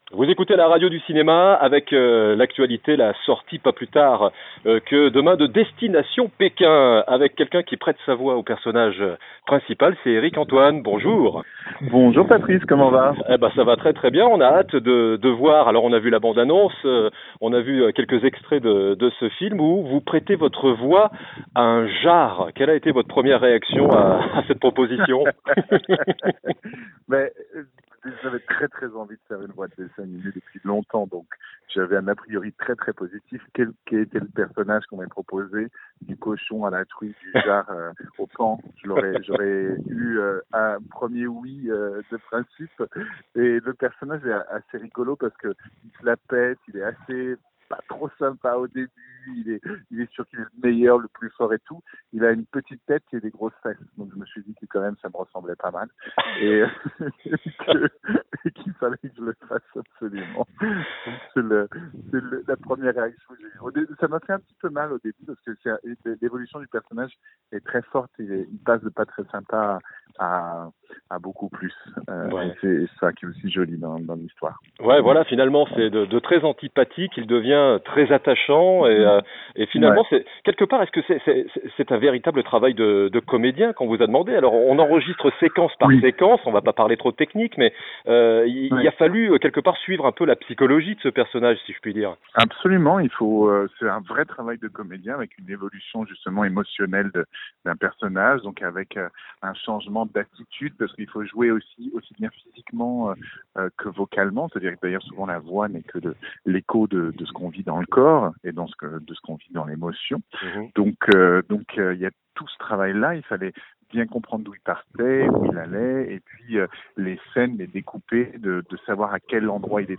Eric Antoine au micro de la radio du cinéma pour la sortie du film d'animation "Destination Pékin"